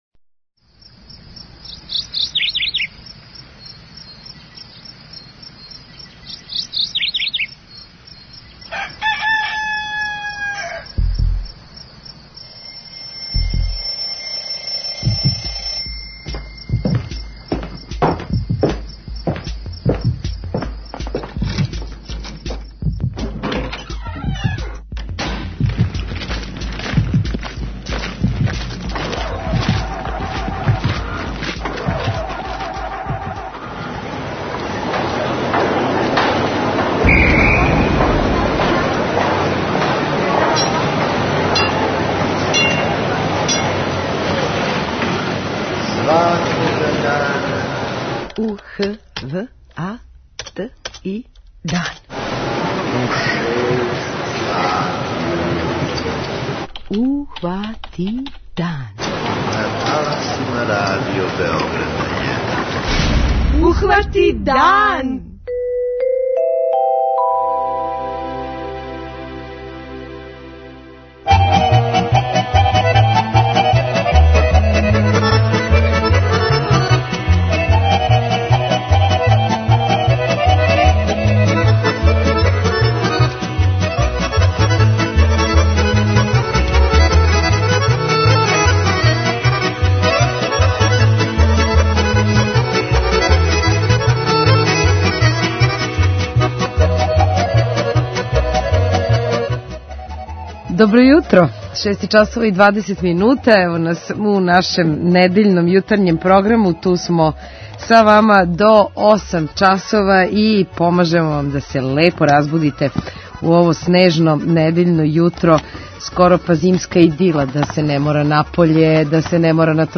преузми : 18.20 MB Ухвати дан Autor: Група аутора Јутарњи програм Радио Београда 1!